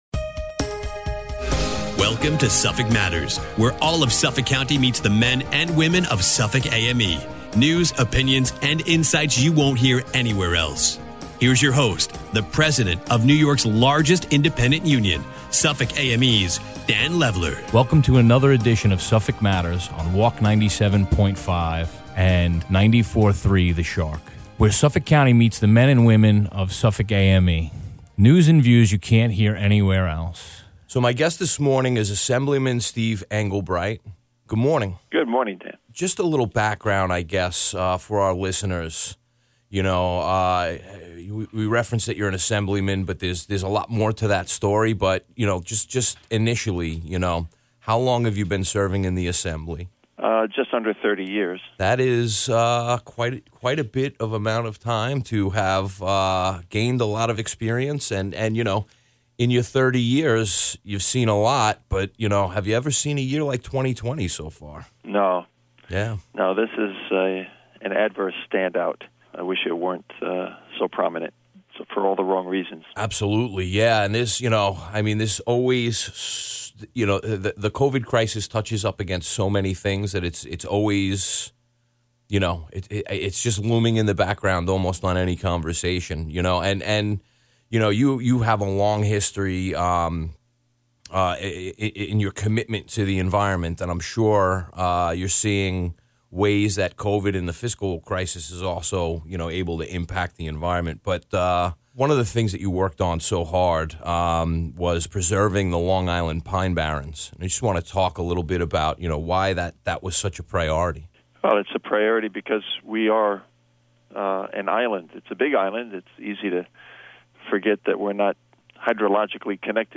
speaks with Assemblyman Steve Englebright - New York State Assembly District 4